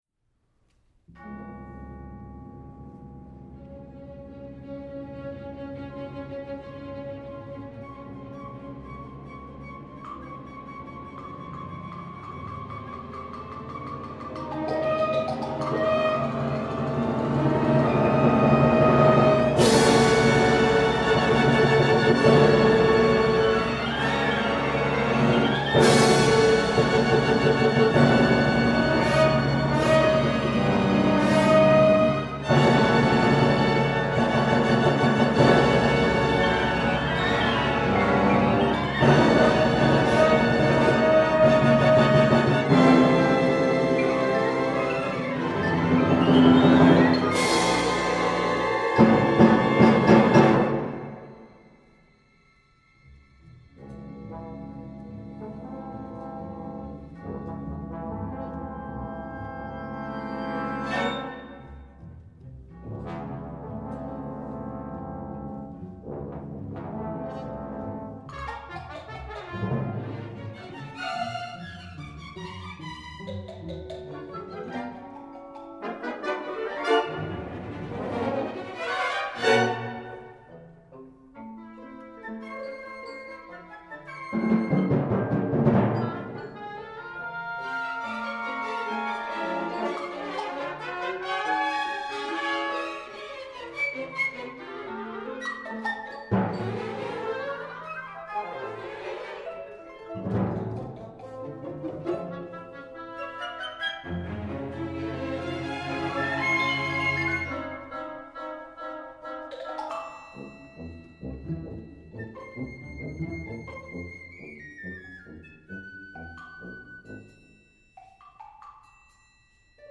• Thriller (00:00);
• Mystery (00:00);
• Horror (00:00);